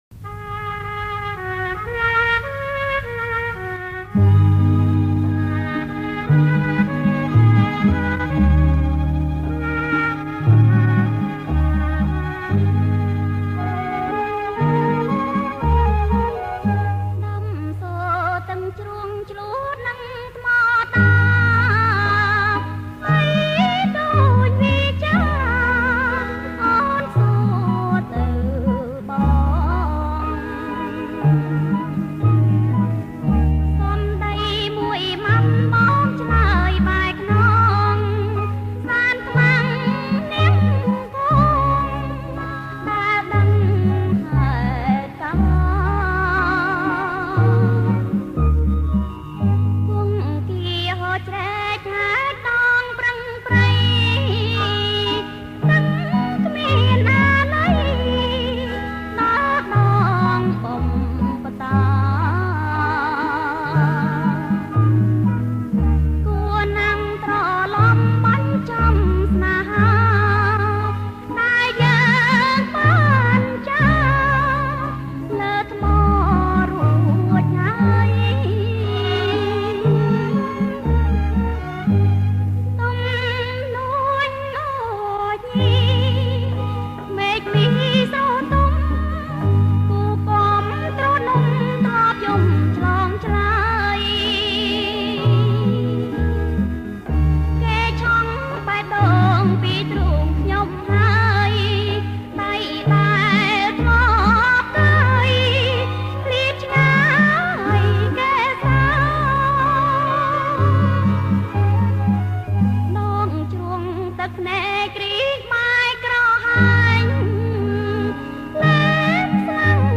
• ប្រគំជាចង្វាក់ Bolero Folk